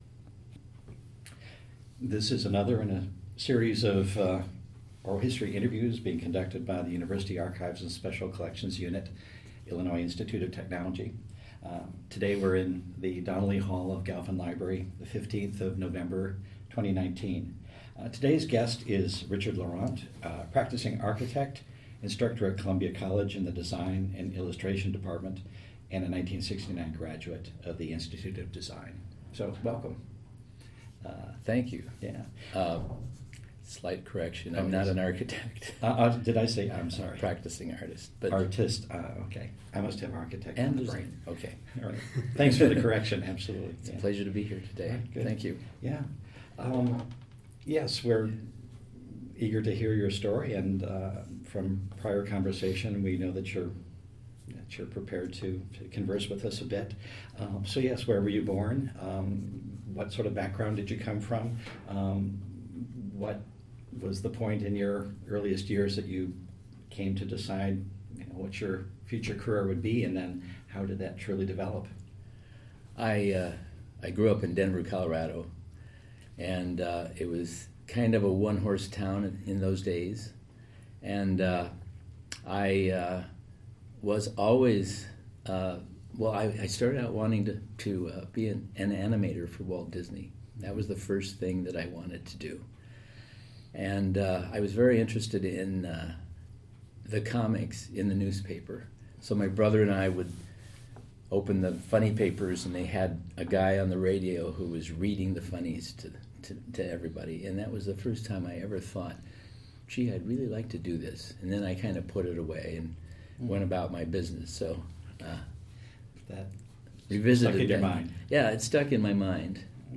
Type Interview